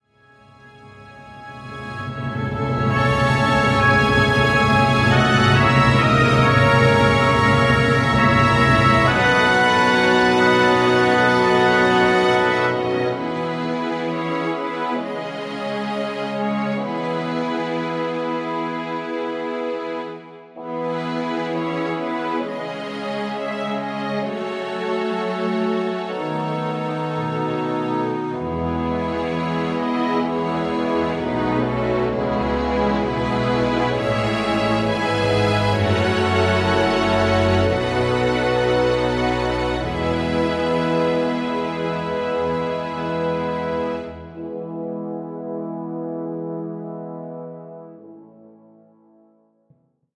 今作品中では珍しくギターを一切使用しておらず、
管弦楽だけでどれだけのことができるかな、という試みとなっております。
クラシックの持ち味とも言えるダイナミクスを極力抑え、